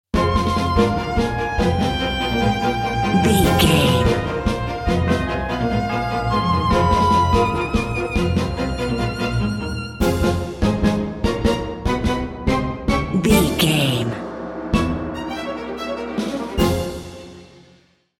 Ionian/Major
Fast
frantic
orchestra
violin
brass section
bells
cello
piccolo
drums
aggressive
harp